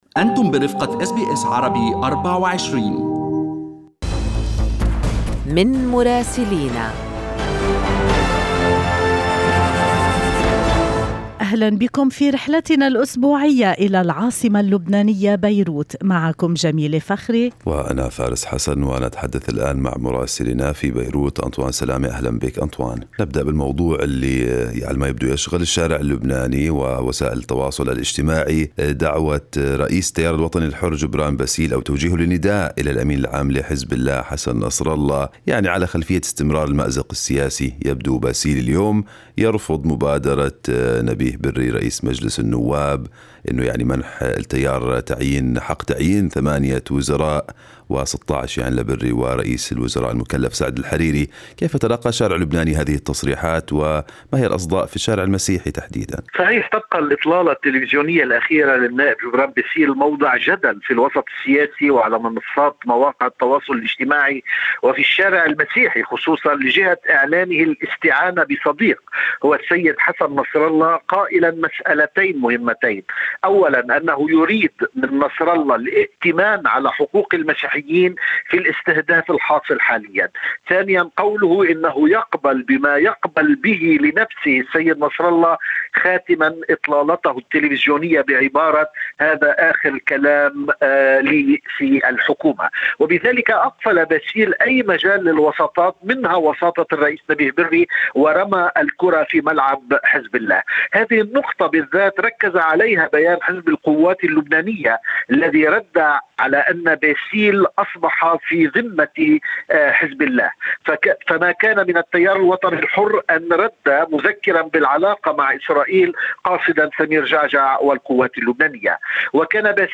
يمكنكم الاستماع إلى تقرير مراسلنا في بيروت بالضغط على التسجيل الصوتي أعلاه.